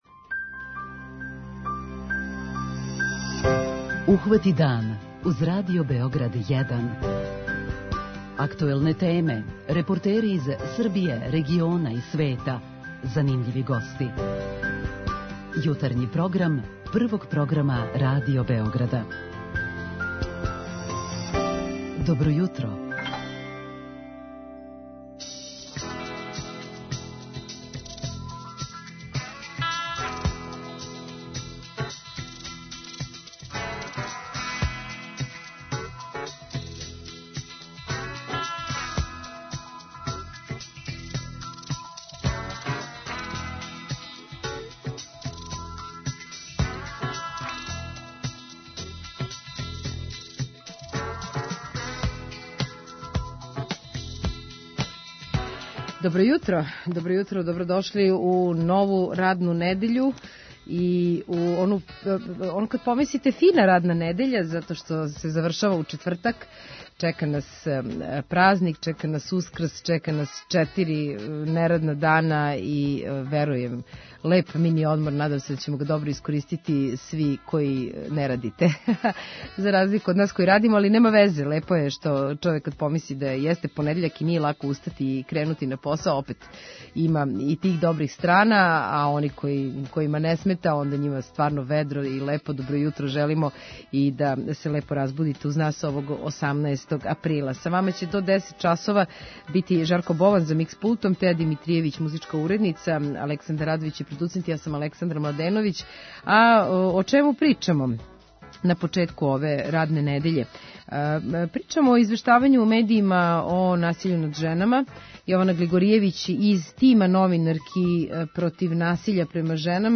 преузми : 37.78 MB Ухвати дан Autor: Група аутора Јутарњи програм Радио Београда 1!